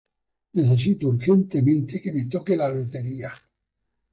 Pronounced as (IPA) /me/